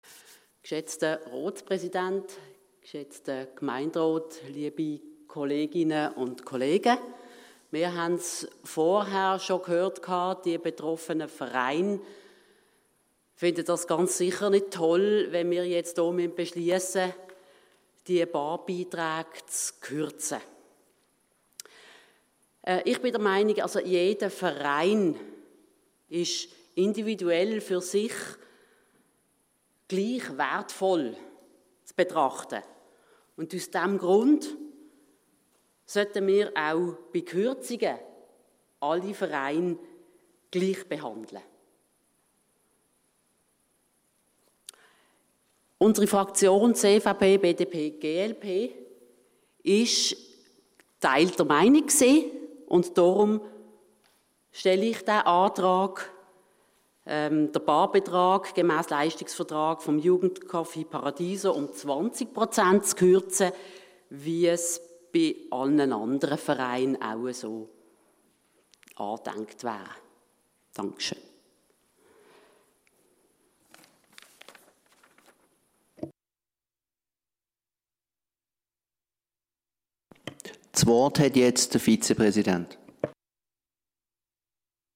Wortmeldung 27/09/2021 -
Gerechtigkeit ist wertvoll. Einsatz für Gleichheit im O-Ton.